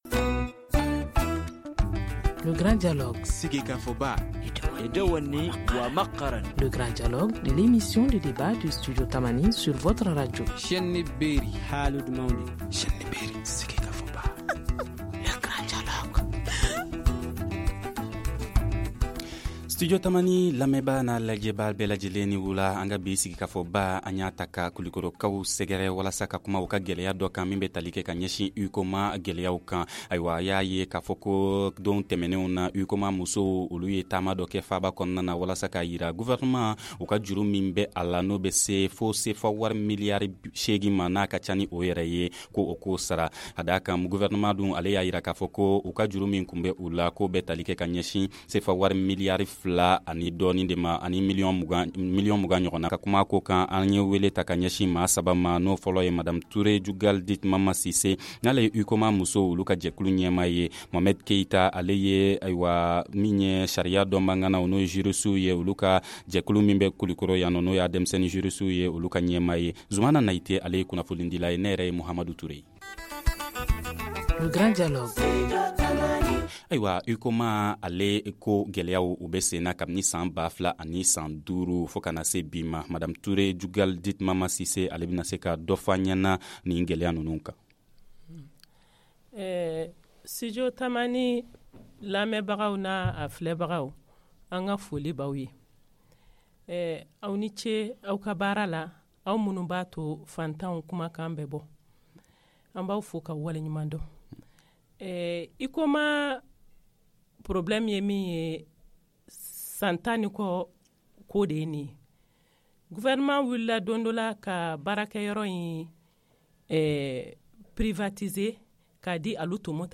Studio Tamani à décidé de délocalisé ce grand dialogue à Koulikoro pour faire le point de la situation.